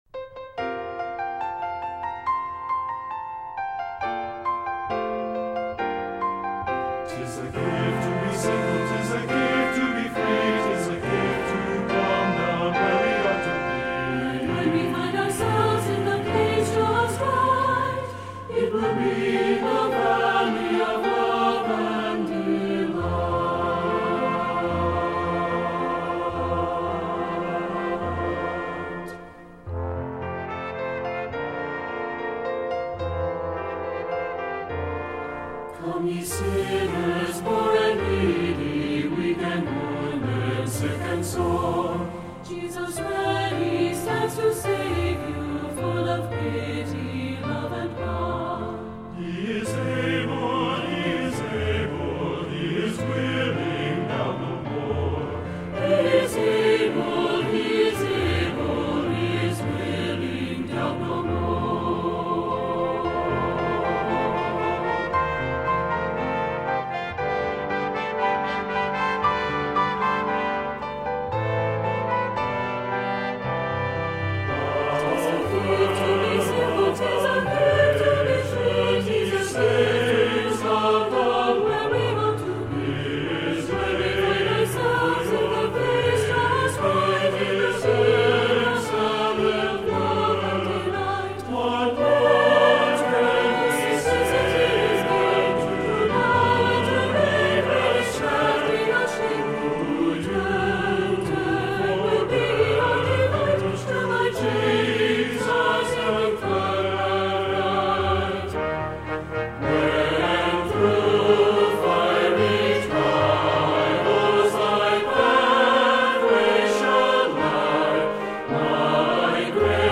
SAB Church Choir Music
Voicing: SATB